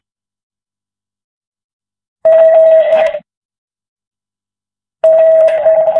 Еще один домофон